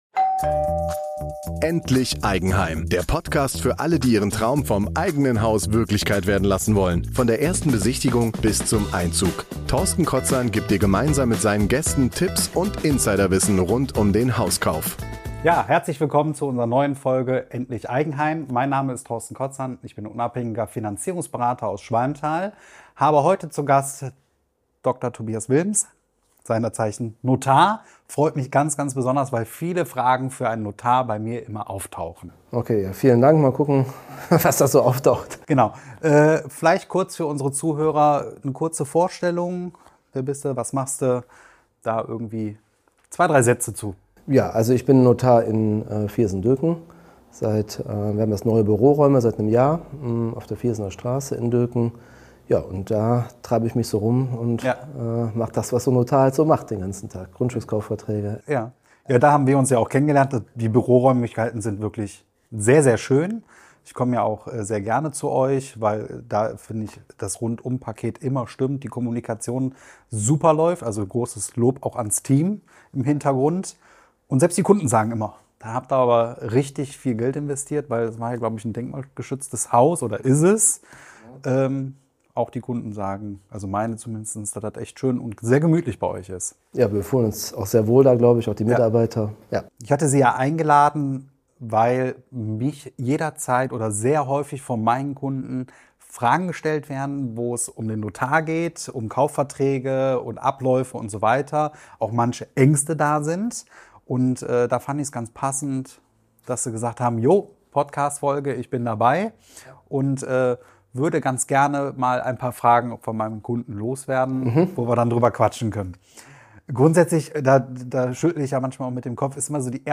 Beim Hauskauf führt kein Weg am Notar vorbei – aber warum eigentlich? In diesem Interview